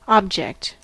object-noun: Wikimedia Commons US English Pronunciations
En-us-object-noun.WAV